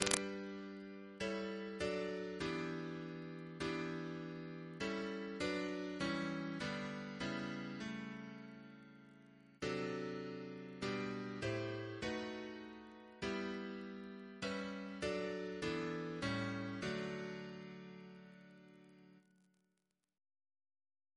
Double chant in D Composer: Sir Edward C. Bairstow (1874-1946), Organist of York Minster Reference psalters: ACP: 237; RSCM: 12